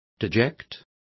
Complete with pronunciation of the translation of dejected.